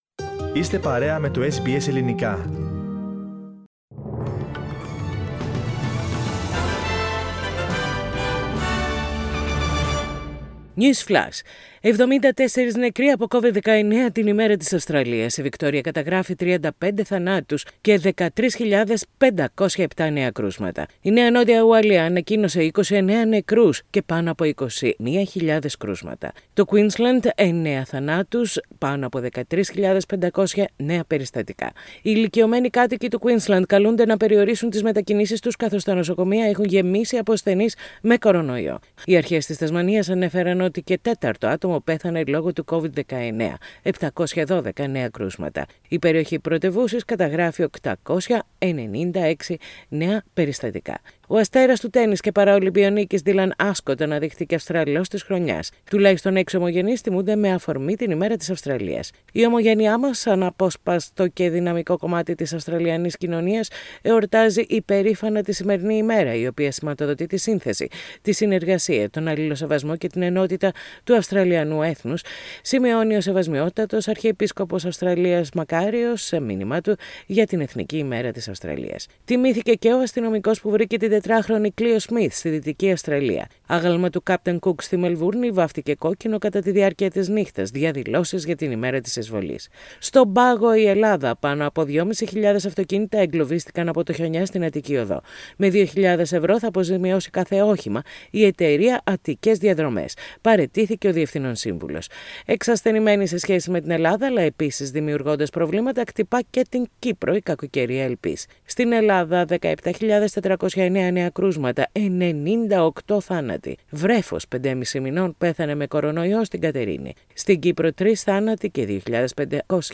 News flash in Greek.